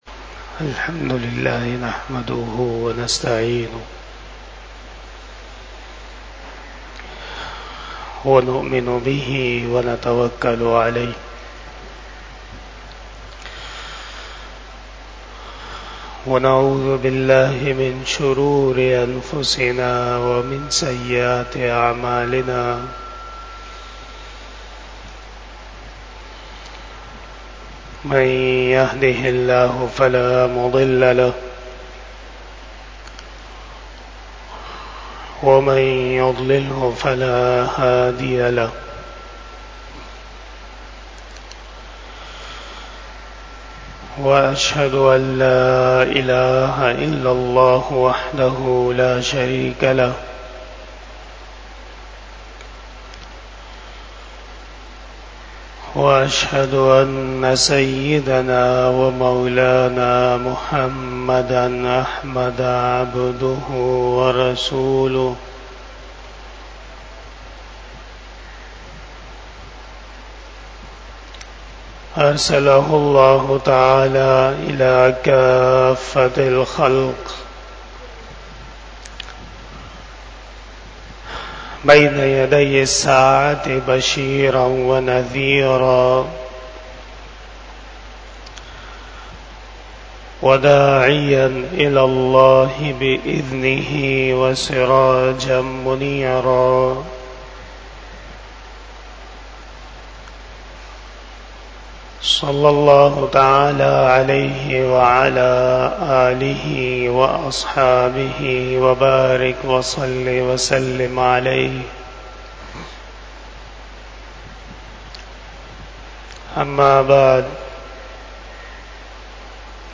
32 Bayan E Jummah 08 August 2024 (03 Safar 1446 HJ)
Khitab-e-Jummah 2024